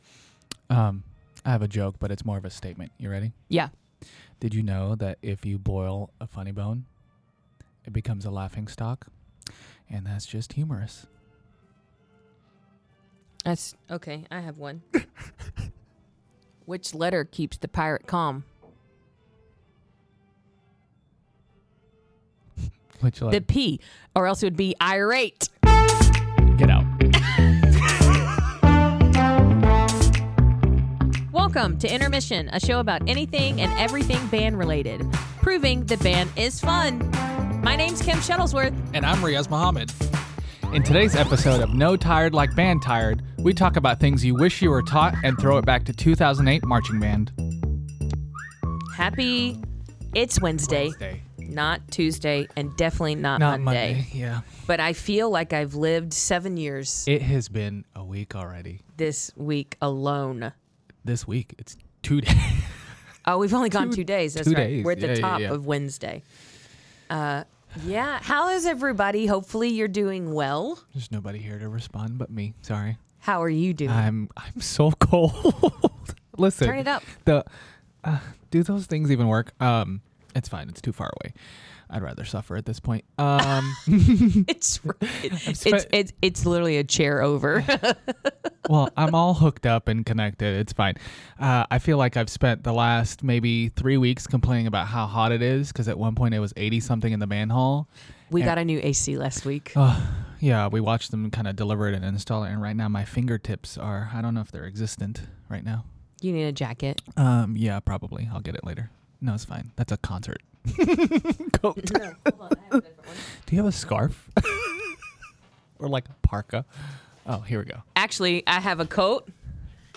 Intermission discusses the day in the life of two Band Directors covering anything and everything band related. We'll share stories and tips based of our experiences in enjoying what we do, having fun, and learning on the daily.